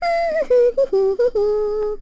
Lilia-Vox_Hum.wav